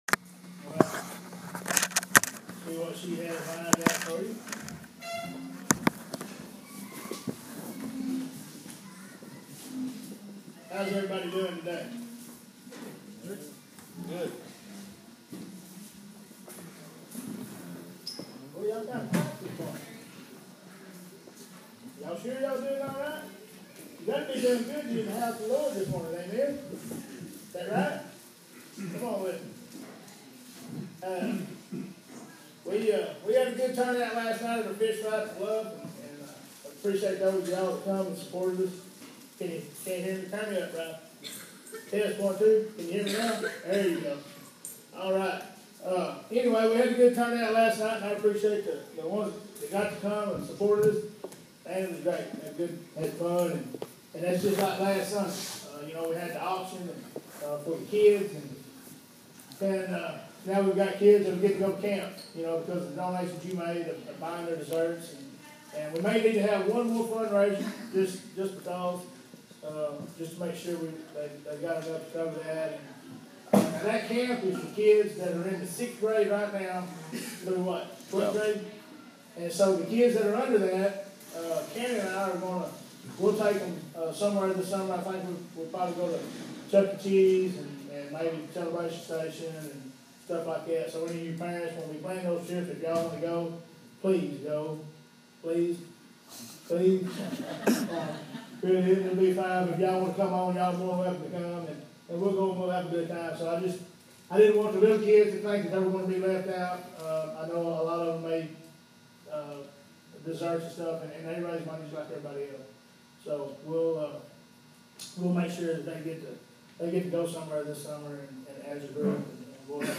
Sermons - Crimson River Church & Ministries